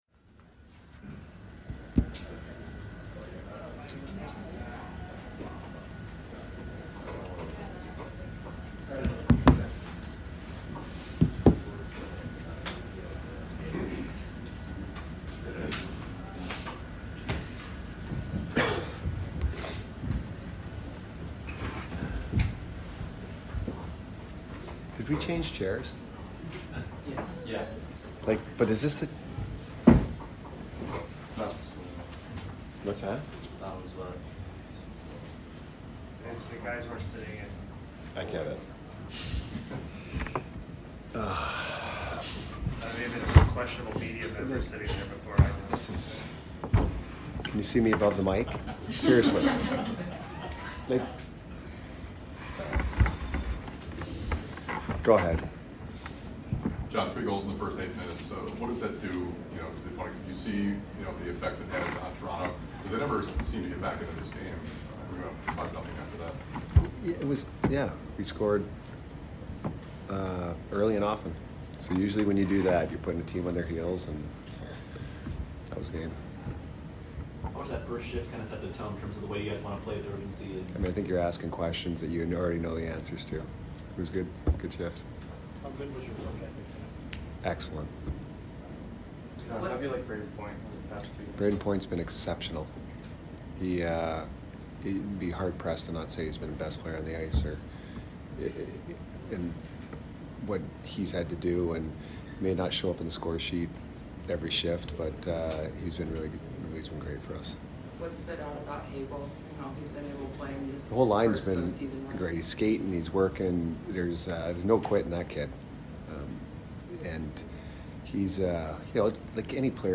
Head Coach Jon Cooper Post Game Vs TOR 5 - 8-2022